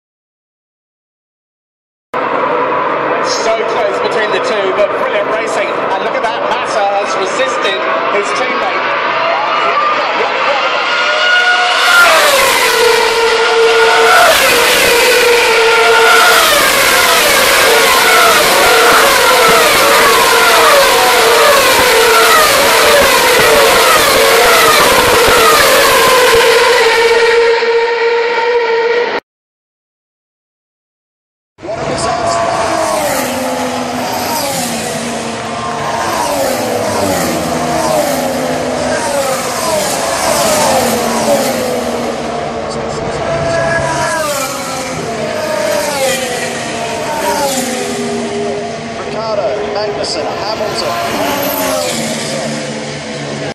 Ce travail pratique met en évidence l’effet Doppler des ondes sonores et permet de mesurer la vitesse d’une voiture grâce à cet effet
Son-Formule_1.mp3